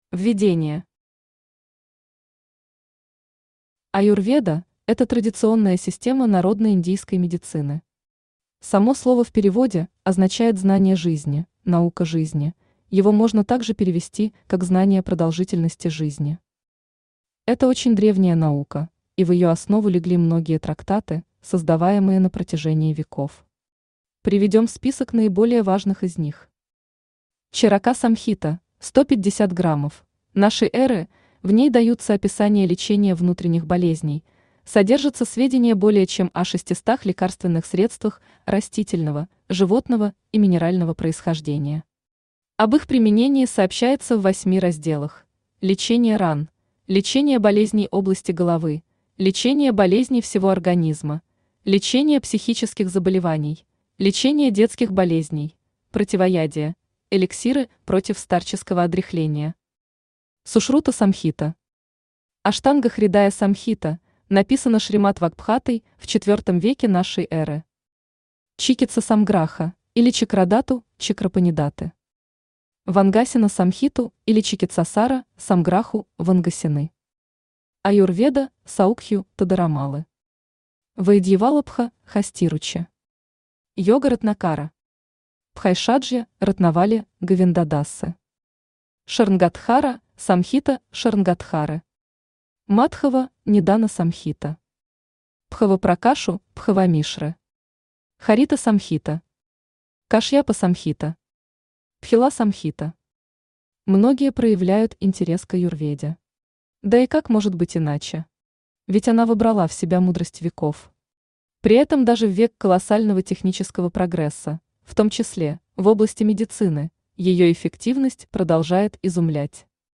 Аудиокнига Аюрведа. Основные понятия | Библиотека аудиокниг
Основные понятия Автор Ашвани Вишвамитра Читает аудиокнигу Авточтец ЛитРес.